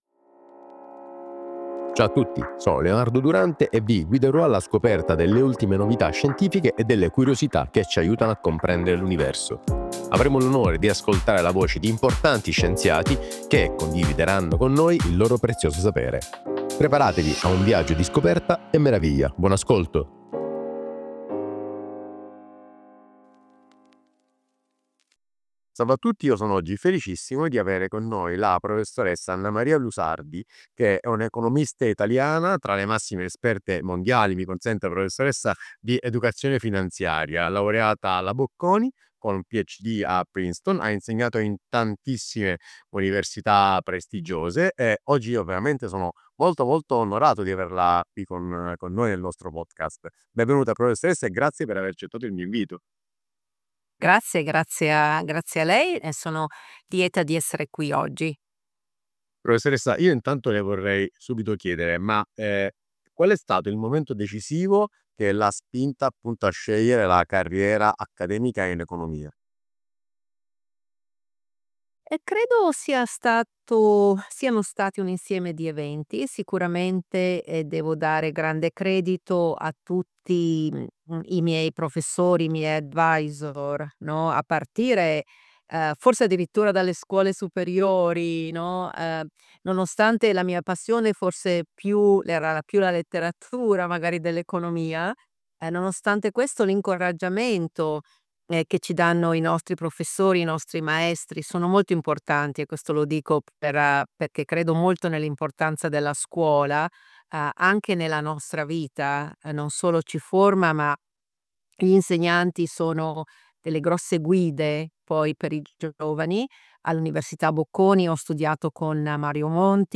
Intervista a Annamaria Lusardi economista di fama internazionale.Stanford